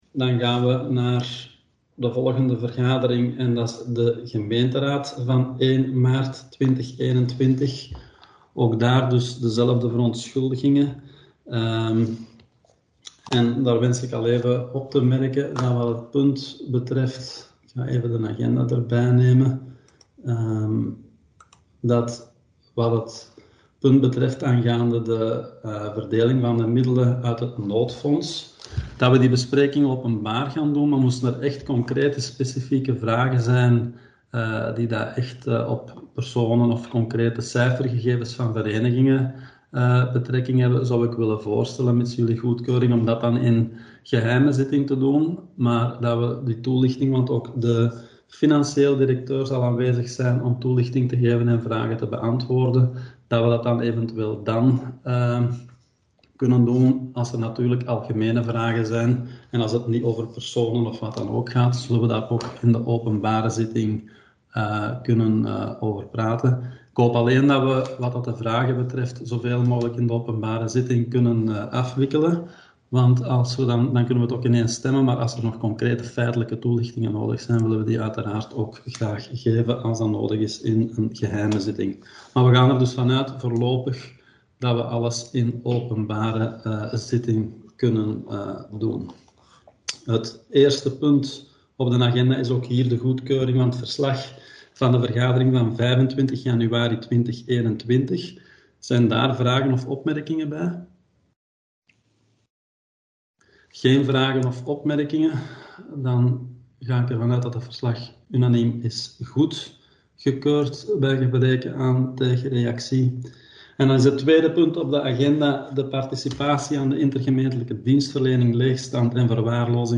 Gemeenteraad 1 maart 2021